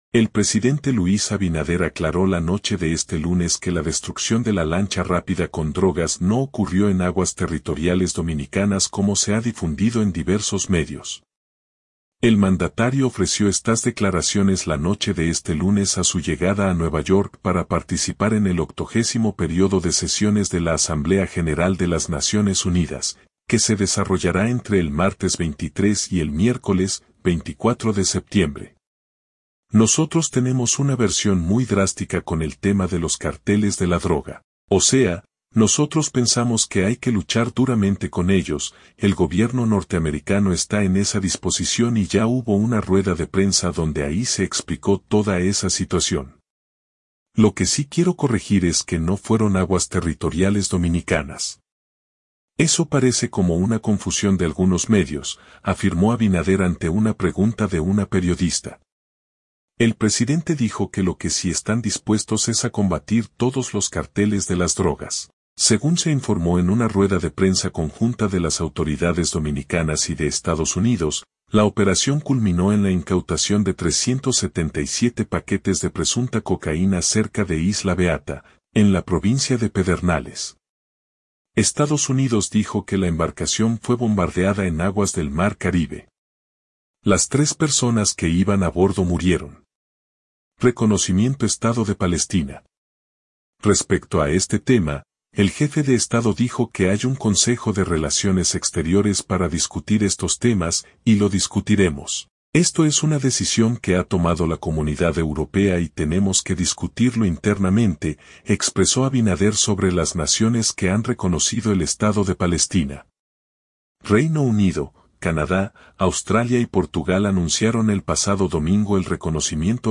El mandatario ofreció estas declaraciones la noche de este lunes a su llegada a Nueva York para participar en el 80.º Período de Sesiones de la Asamblea General de las Naciones Unidas, que se desarrollará entre el martes 23 y el miércoles 24 de septiembre.